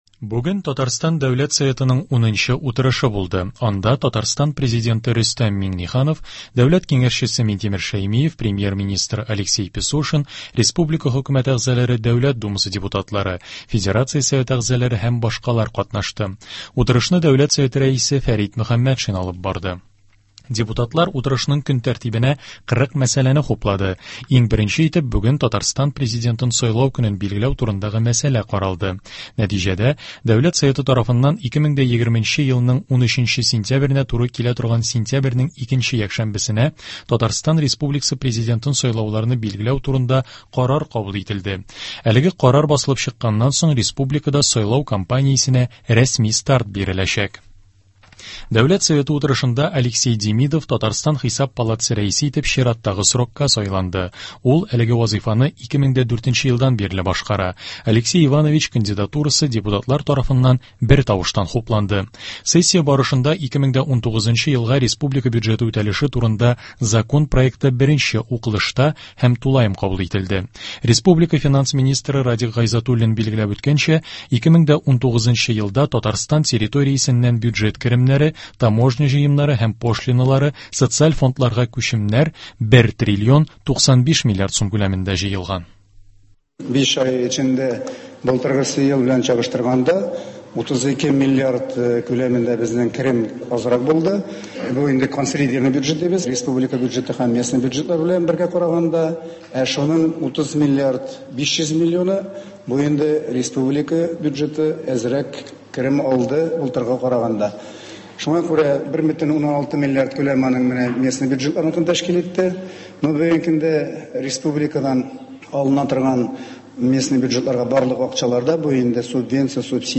Радиоотчет. 11 июня.